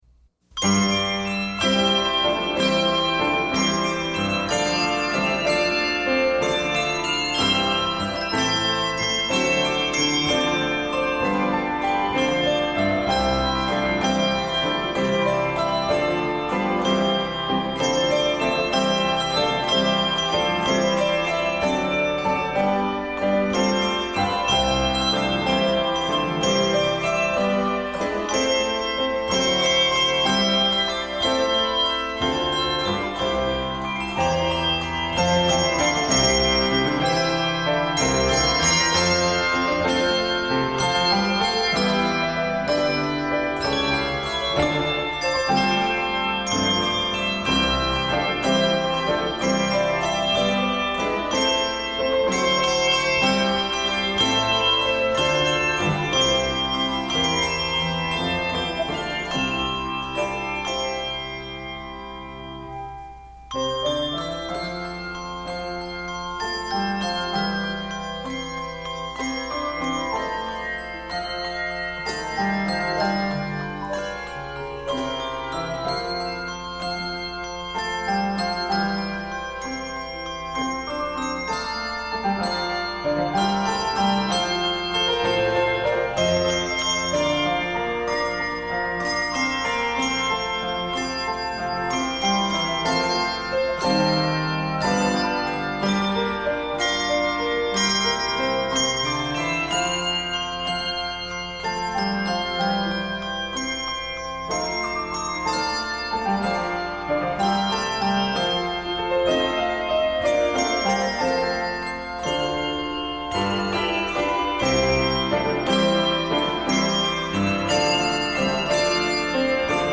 both lively and uplifting
Keys of C Major and F Major.